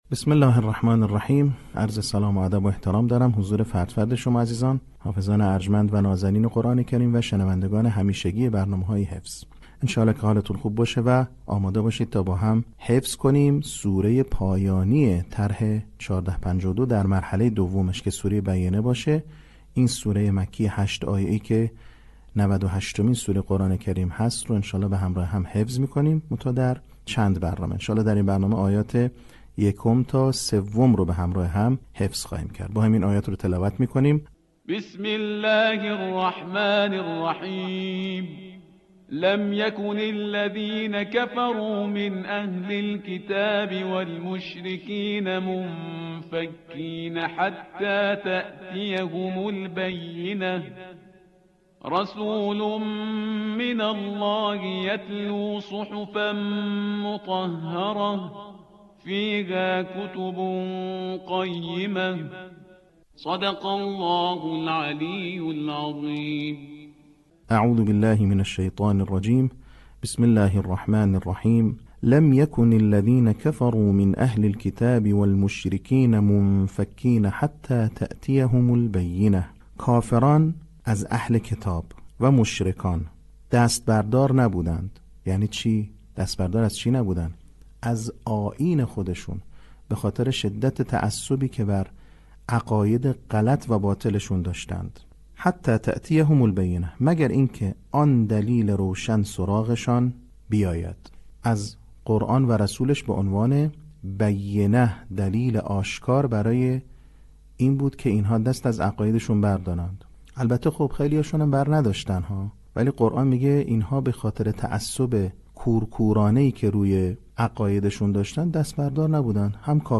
صوت | آموزش حفظ سوره بینه